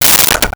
Plastic Bowl 02
Plastic Bowl 02.wav